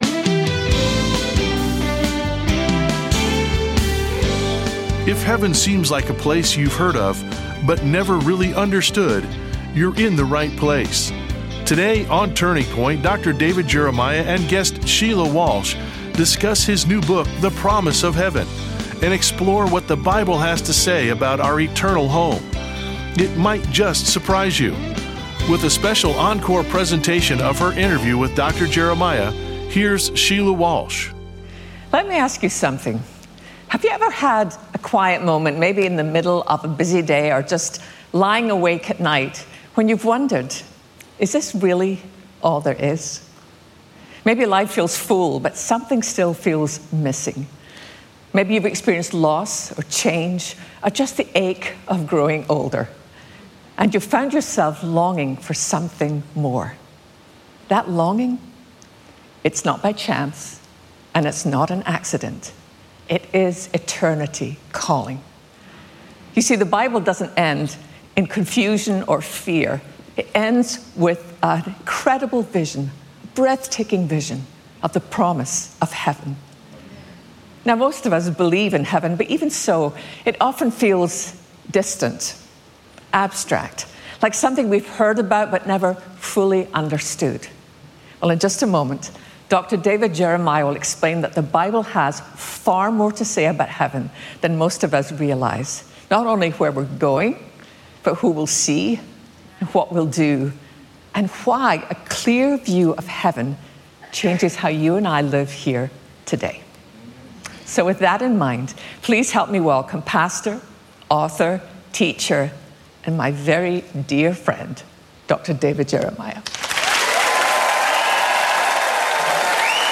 In this special interview, Dr. David Jeremiah sits down with Sheila Walsh to answer some of the most common and heartfelt questions about heaven and eternity. This honest and encouraging conversation brings clarity, comfort, and a deeper longing for our eternal home.